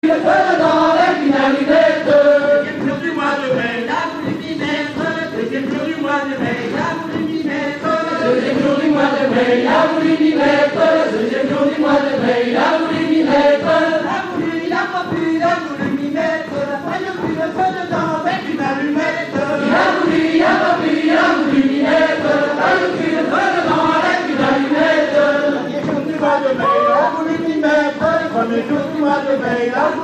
Musique : Traditionnel
Origine : Bretagne
Danse : Rond de Saint-Vincent, Ridée
artistes divers - chants dans la ronde recueillis en haute-bretagne - 21-il a voulu my mettre (ridee 6 temps).mp3